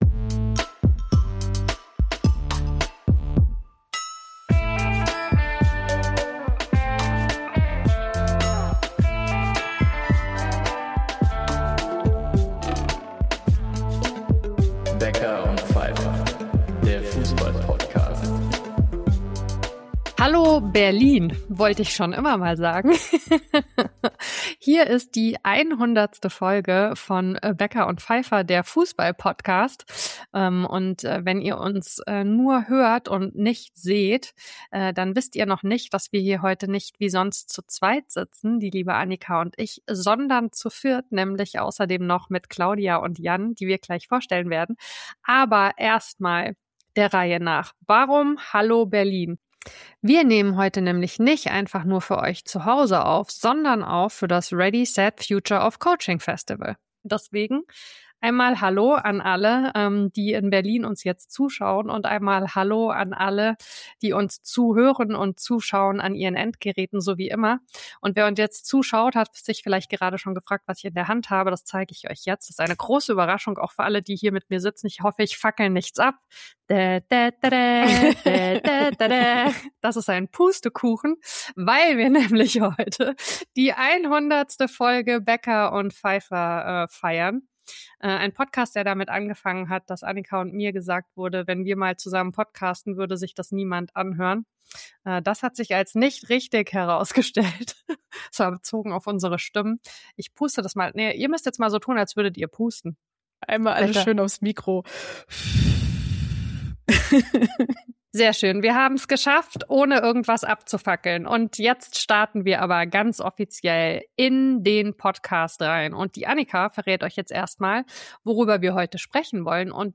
Unsere 100. Folge hatte am 7. März 2026 Premiere auf dem Ready. Set. Future of Coaching Festival für Mädchen, Frauen, inter*, nicht-binäre, trans* und agender Personen im Fußballcoaching, organisiert Berliner Fußball-Verband.